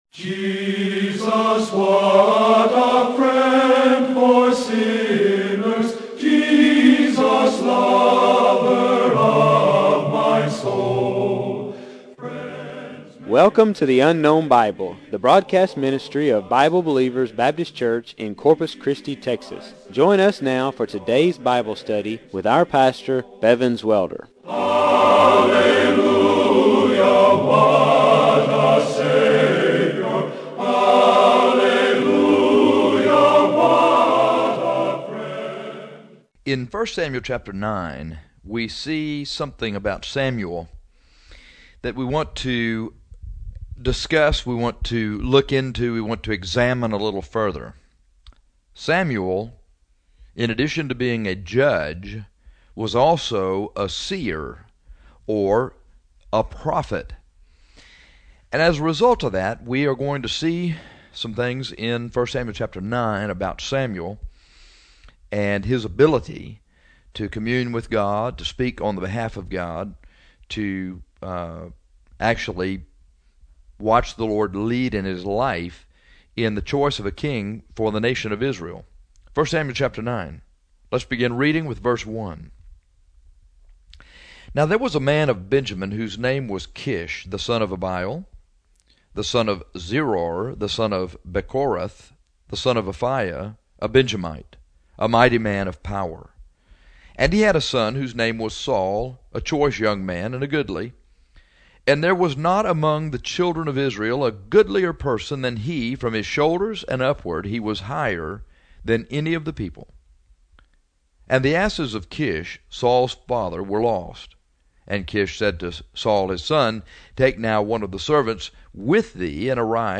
In 1 Sam 9:1-27 we see some outstanding characteristics of Samuel, the Seer. Listen to this radio broadcast about this great prophet.